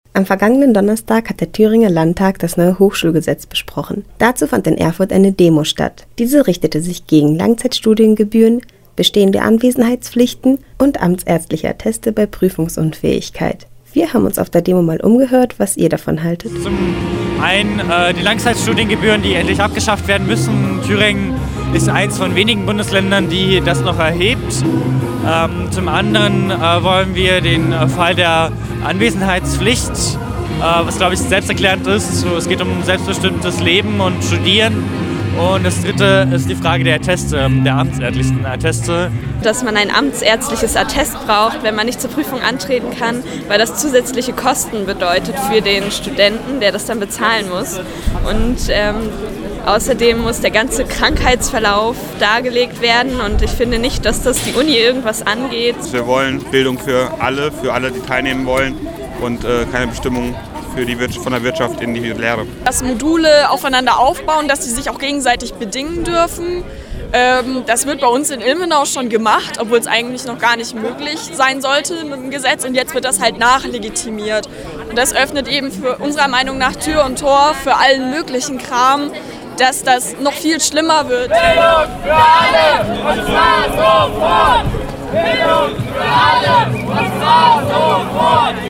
Umfrage: Das neue Hochschulgesetz in Thüringen – Campusradio Jena
Zu dem neuen Hochschulgesetz in Thüringen besteht noch sehr viel Diskussionsbedarf. Wir haben uns auf der Demo, die vergangenen Donnerstag in Erfurt stattfand, einmal umgehört. Das sind einige der Themen, die den Demonstranten am wichtigsten sind: